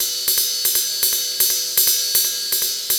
Ride 03.wav